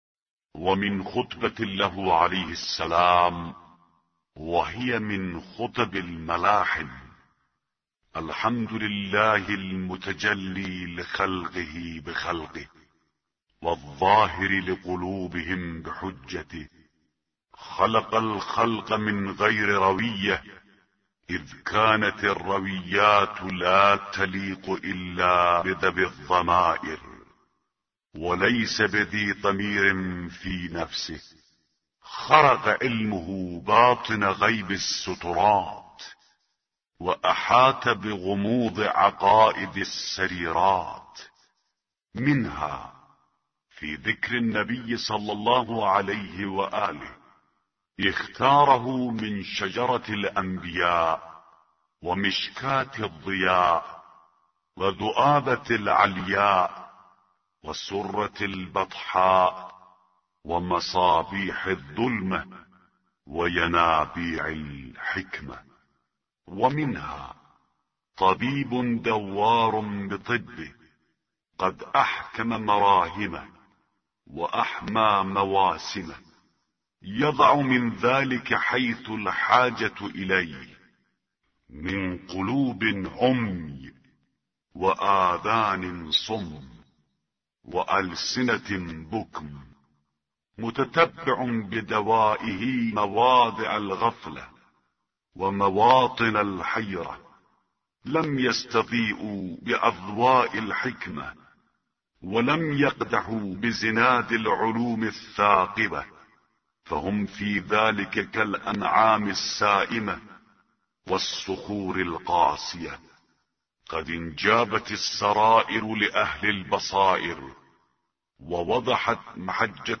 به گزارش وب گردی خبرگزاری صداوسیما؛ در این مطلب وب گردی قصد داریم، خطبه شماره ۱۰۸ از کتاب ارزشمند نهج البلاغه با ترجمه محمد دشتی را مرور نماییم، ضمنا صوت خوانش خطبه و ترجمه آن ضمیمه شده است: